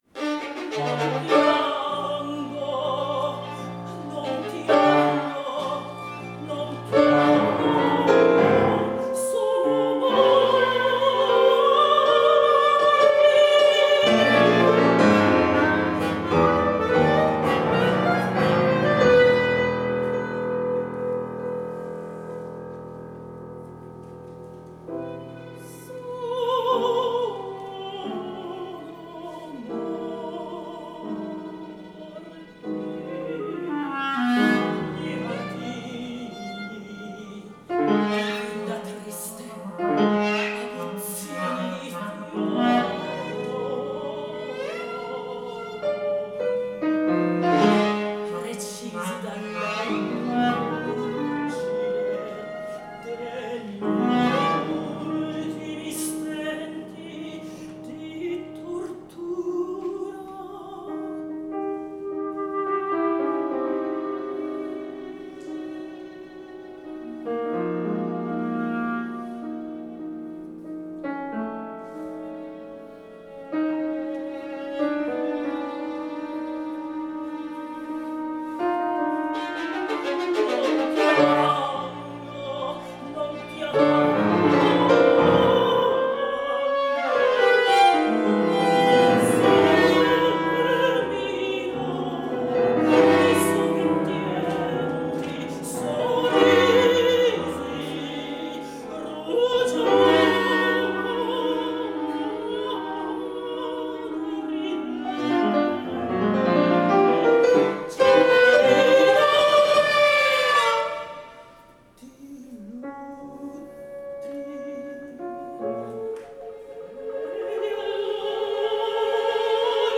per voce, clarinetto, violino e pianoforte
for voice, clarinet, violin and piano
Voice
Clarinet
Violin